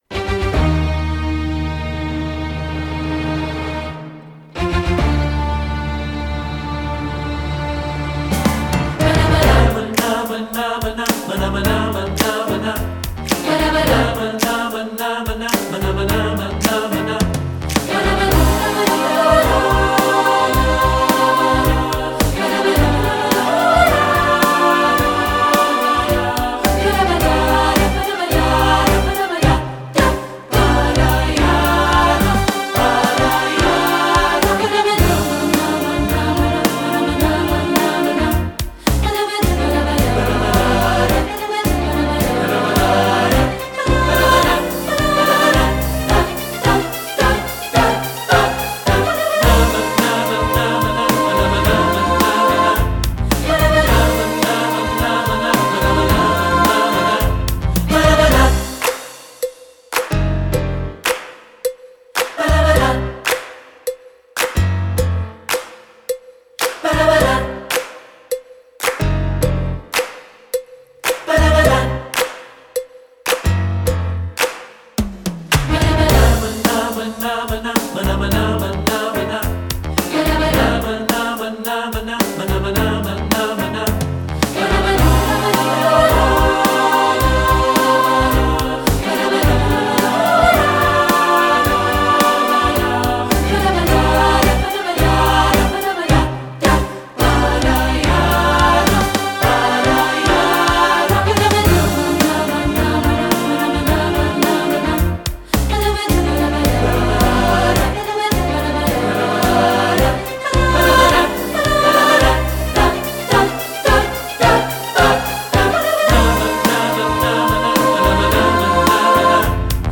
Voicing: 3-Part and Piano